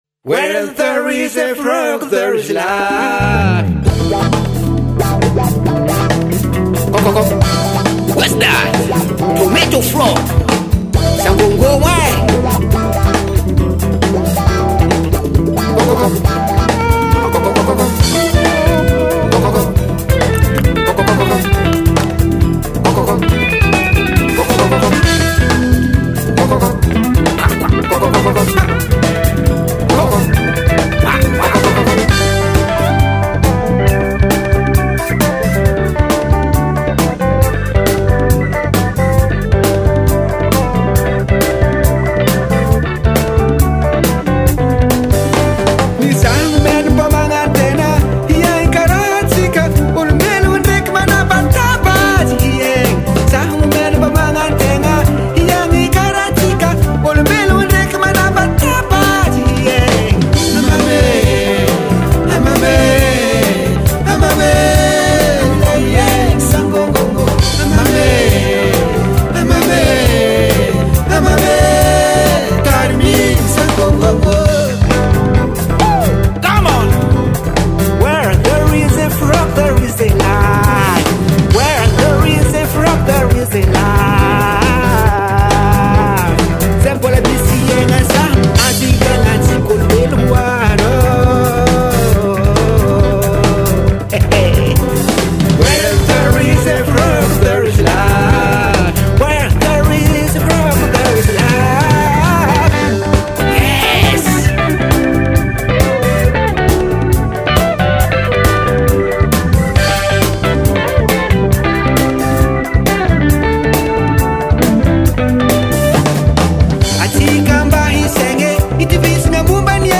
folk group